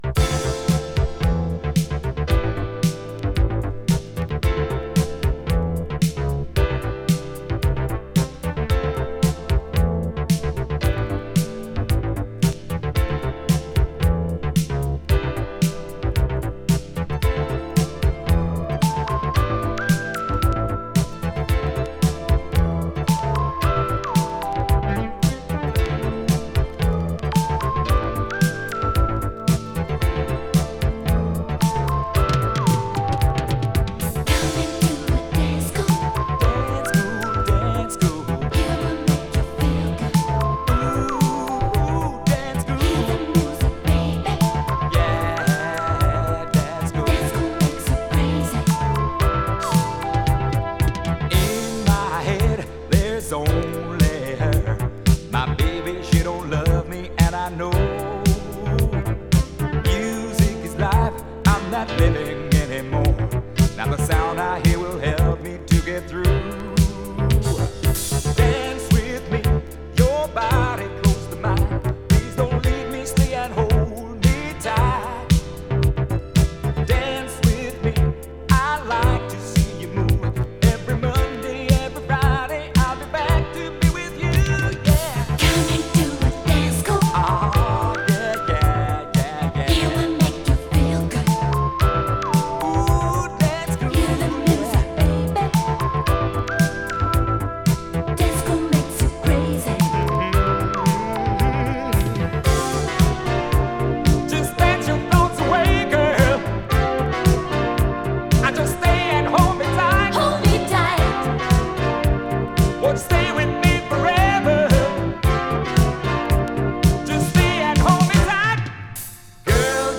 ITALO DISCO CLASSIC!
[ITALO DISCO]